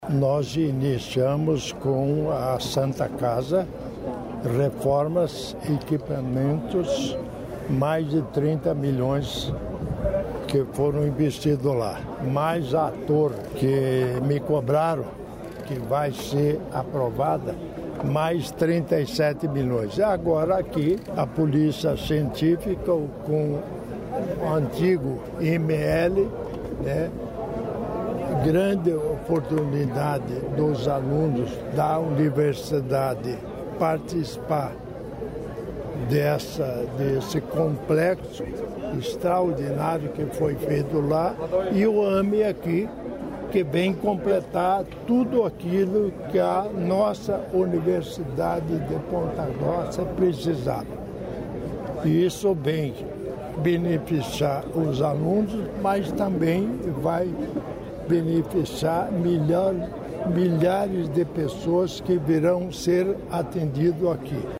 Sonora do vice-governador, Darci Piana, sobre primeiro AME universitário do Brasil em Ponta Grossa